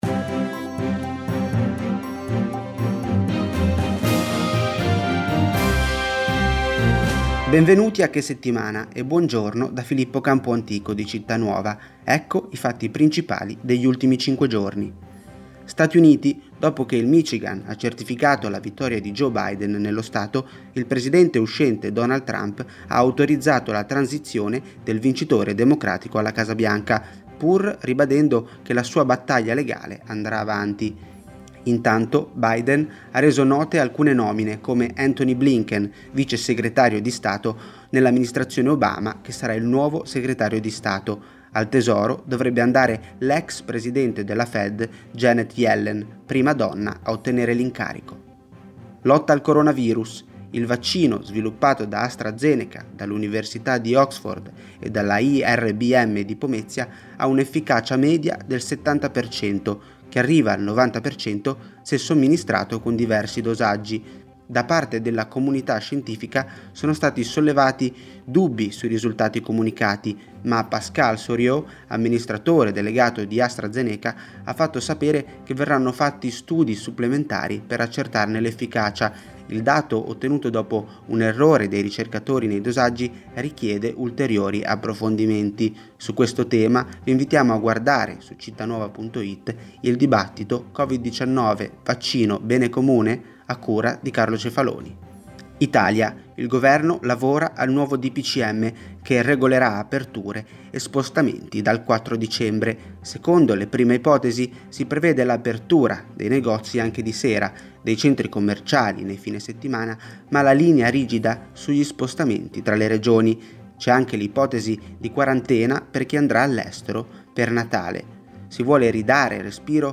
Italia > Audioletture
rassegna-stampa-settimanale.mp3